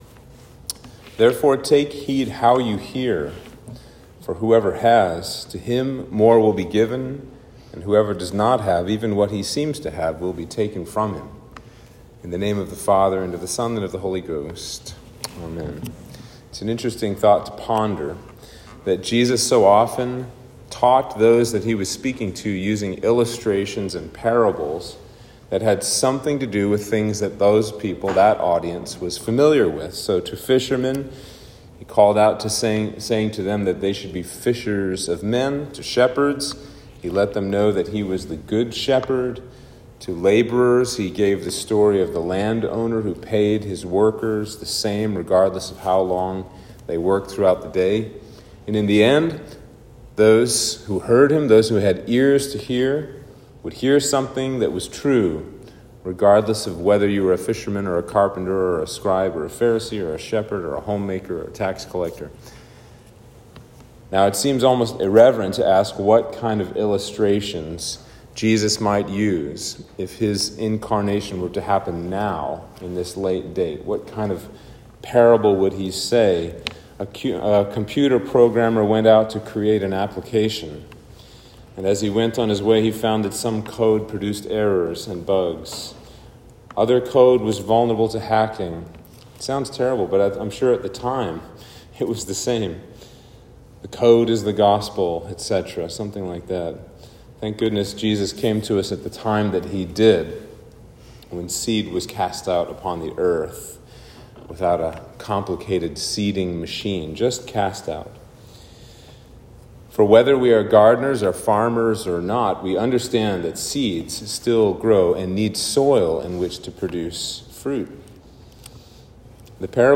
Sermon for Sexagesima